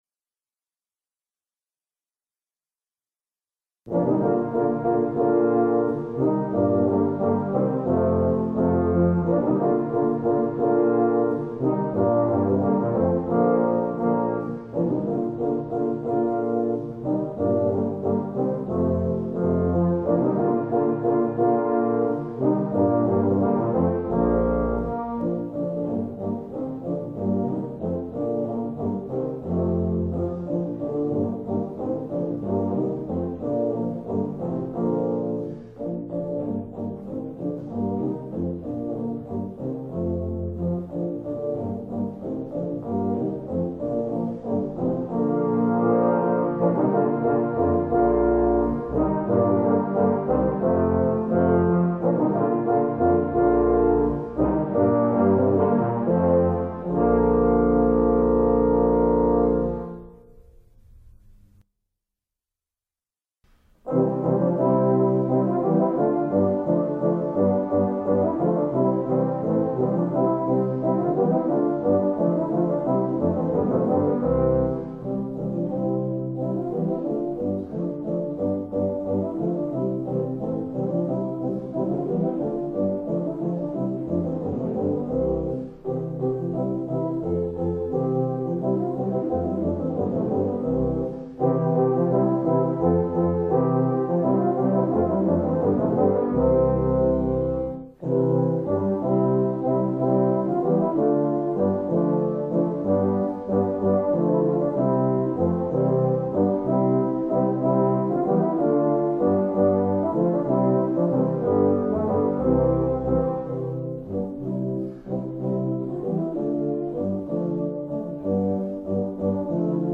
Voicing: Tuba/Euph